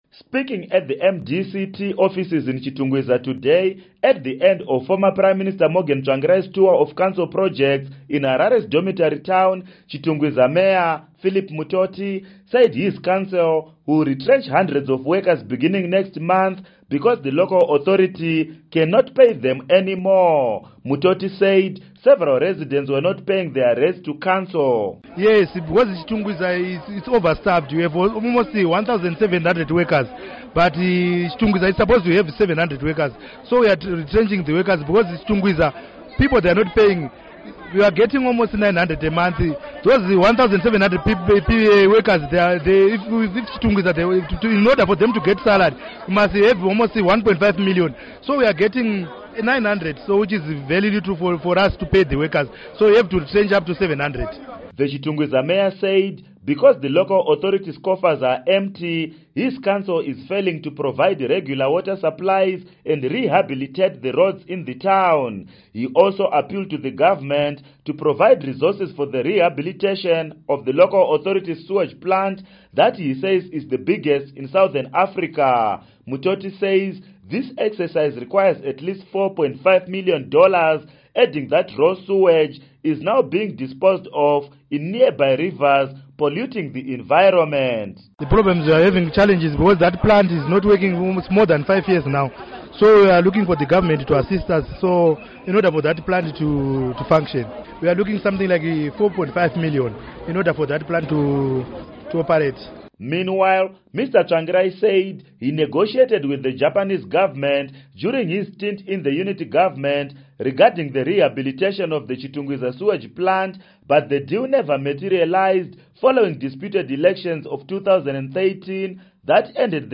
Report on Chitungwiza Retrenchments